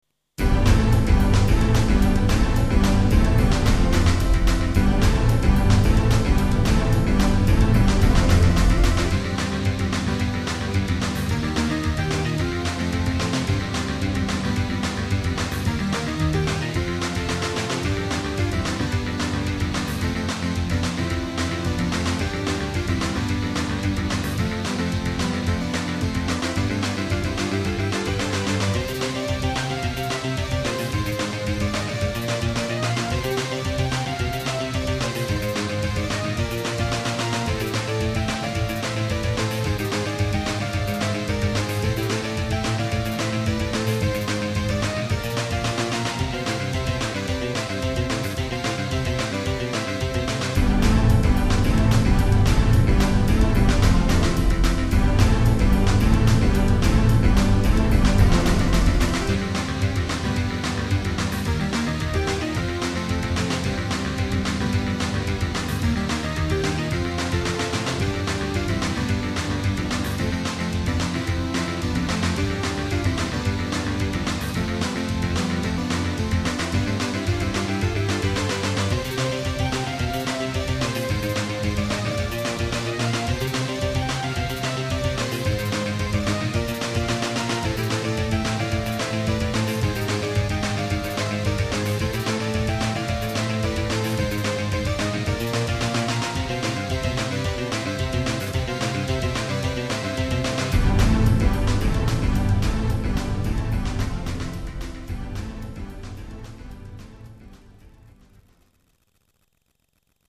※データは全てSC-88Pro専用です